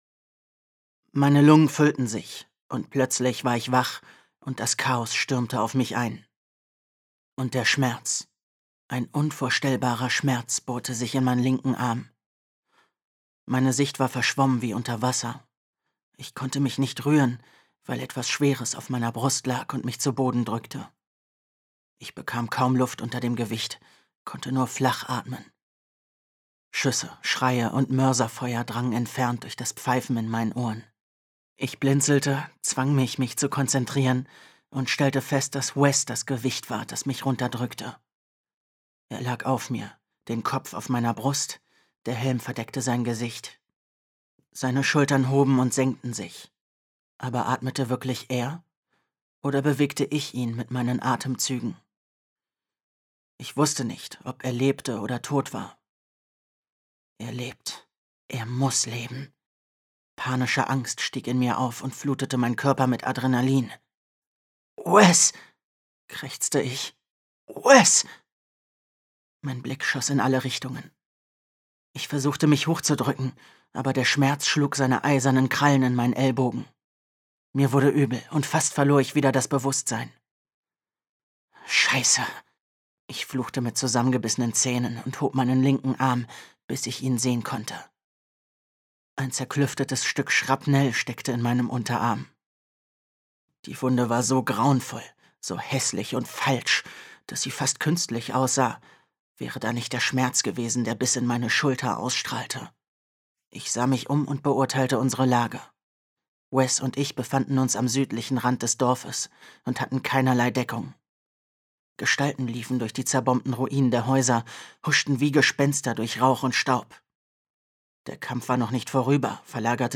2020 | Ungekürzte Lesung